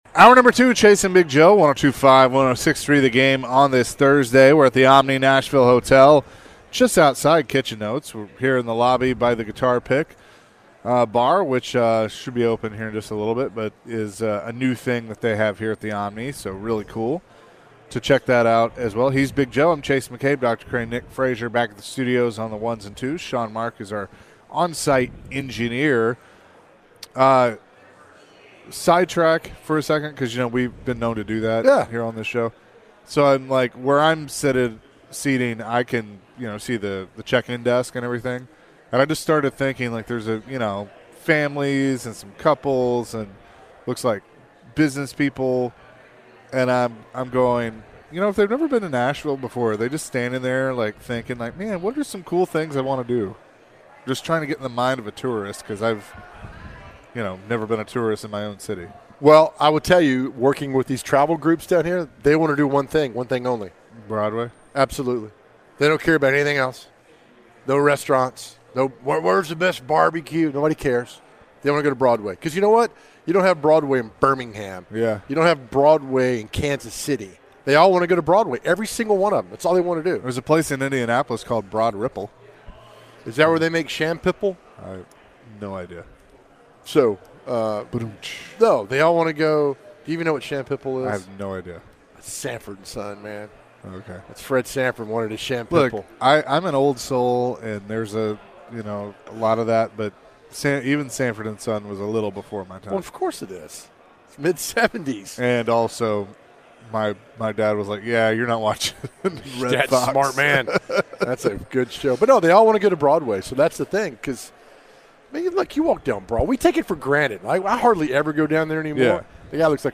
What was the most important decision you made in your life? Later in the hour, the guys answered some phones surrounding the question.